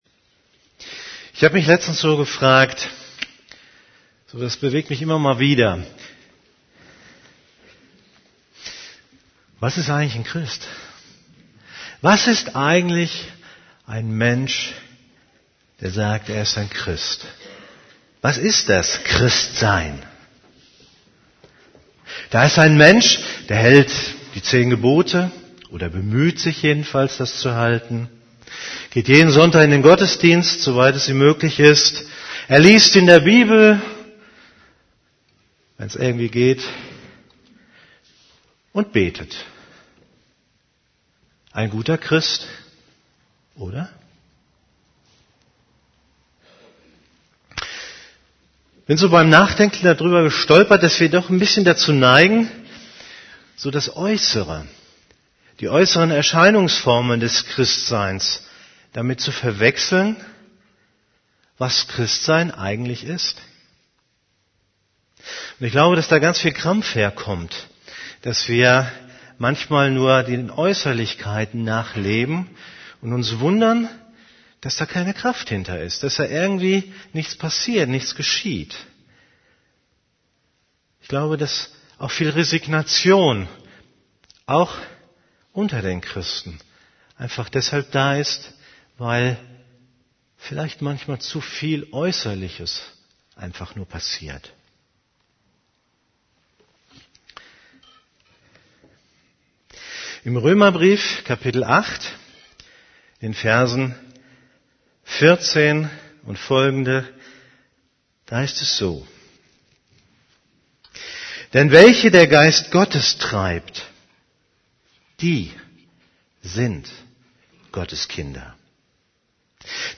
> Übersicht Predigten Lass dich vom Geist Gottes leiten Predigt vom 09.